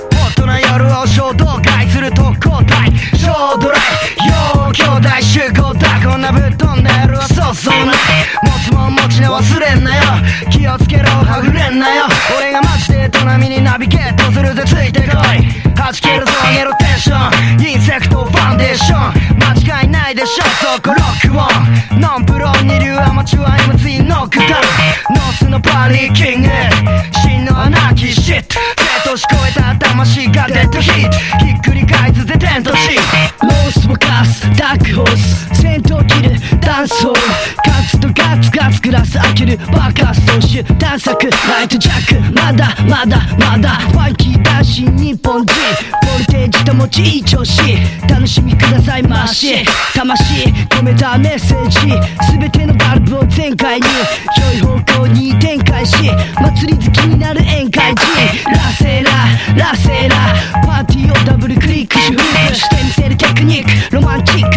MIXTURE / JAPANESE HIP HOP / JAPANESE PUNK
国産ミクスチャー・ロック！